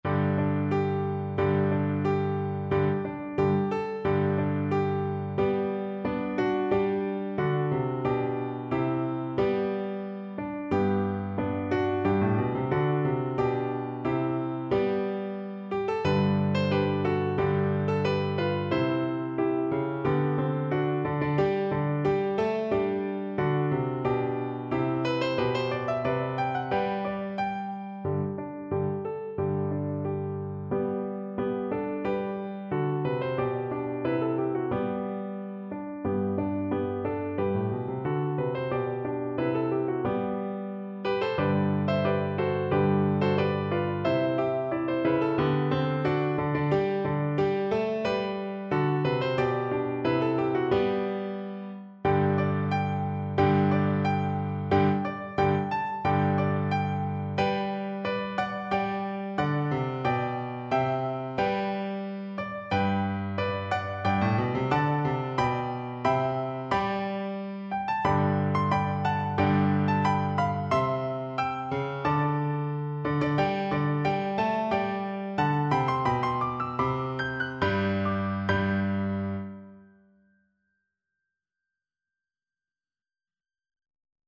Free Sheet music for Soprano (Descant) Recorder
Play (or use space bar on your keyboard) Pause Music Playalong - Piano Accompaniment Playalong Band Accompaniment not yet available transpose reset tempo print settings full screen
G major (Sounding Pitch) (View more G major Music for Recorder )
March ( = c. 90)
2/2 (View more 2/2 Music)
Traditional (View more Traditional Recorder Music)